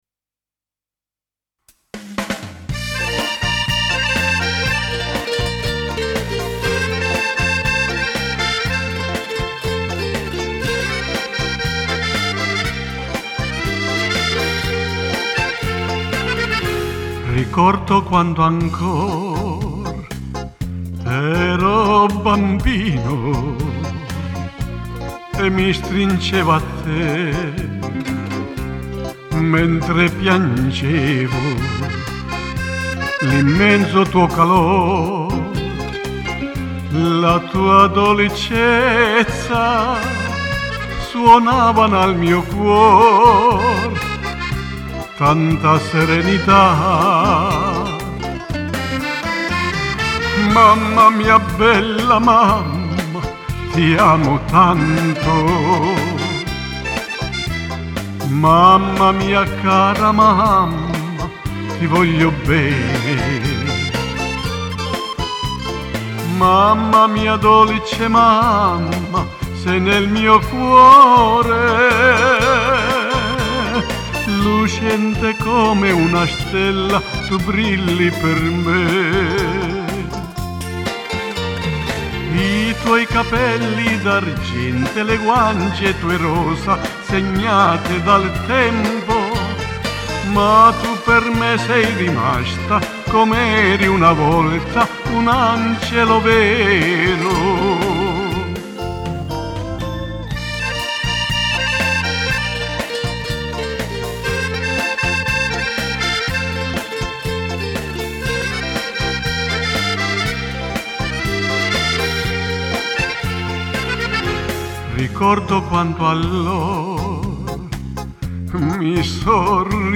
ASCOLTA ALCUNI BRANI CANTATI